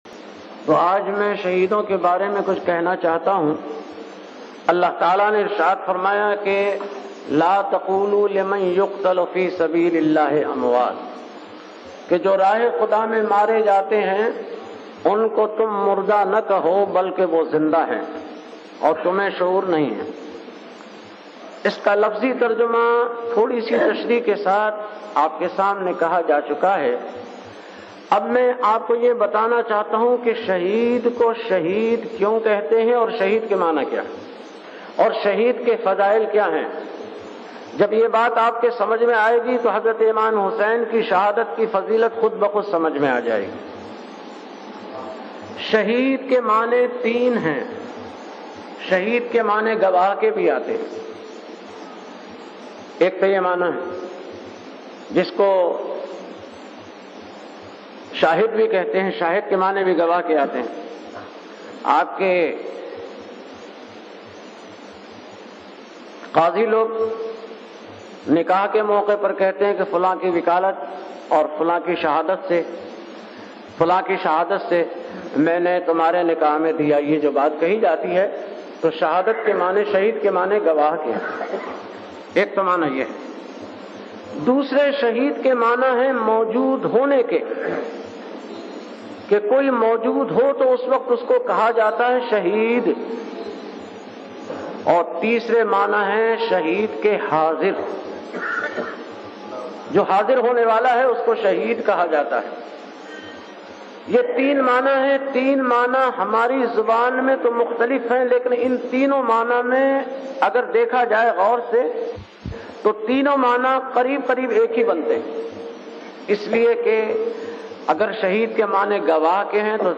Category : Speech | Language : Urdu